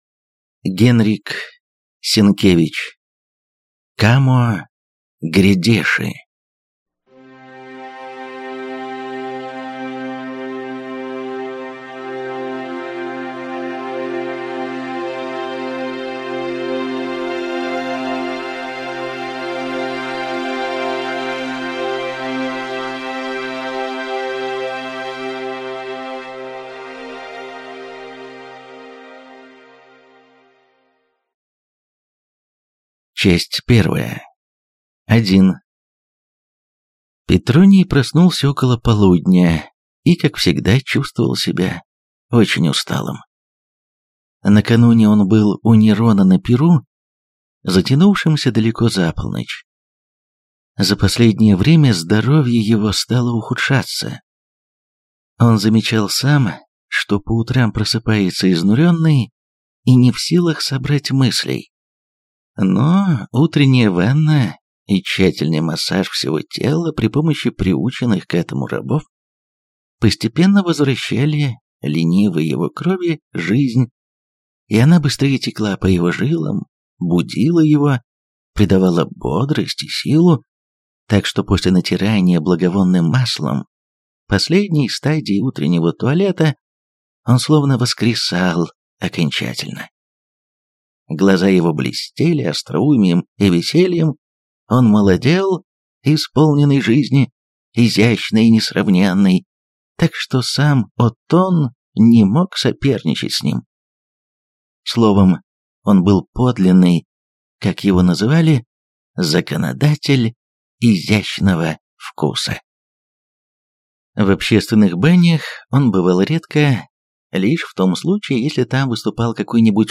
Аудиокнига Камо грядеши. Часть 1 | Библиотека аудиокниг